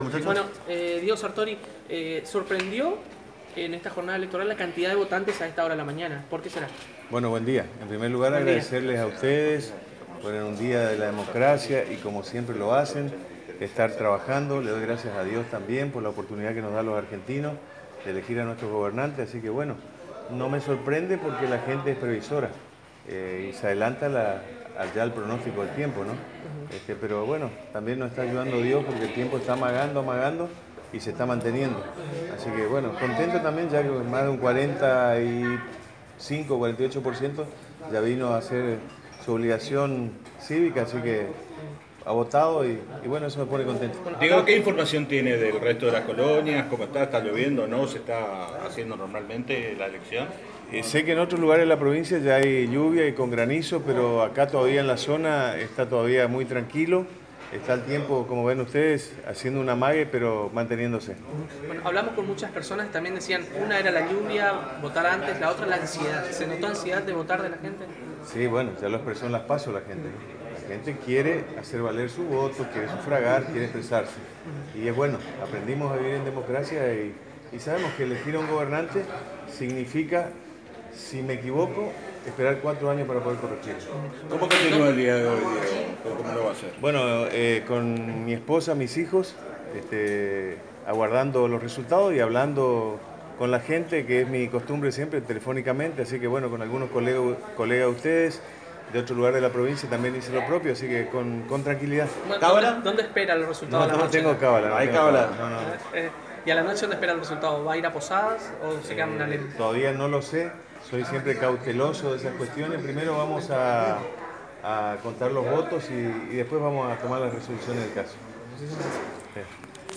En horas del mediodía el Intendente de Leandro N. Alem y Candidato a Diputado Nacional por la Renovación, Diego Horacio Sartori emitió su voto en la Escuela Nº 883 de la ciudad de Leandro N Alem y tras cumplir con su obligación cívica brindó una conferencia a la prensa presente, afirmando que ya votaron más del 48% del electorado y los comicios en Alem se desarrollan con normalidad.
Diego-Sartori-Entrevista.mp3